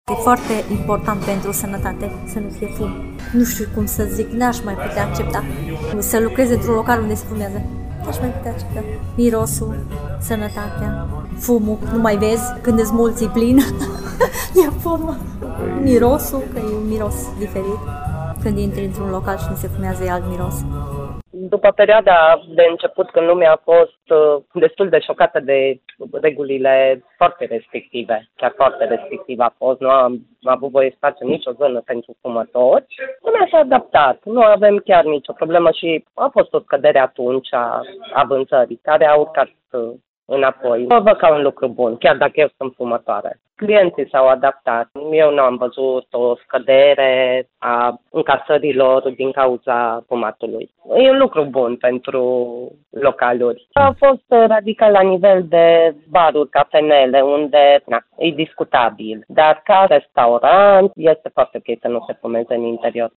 Ce spun mai mulți clujeni despre efectele legii anti-fumat?